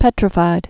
(pĕtrə-fīd)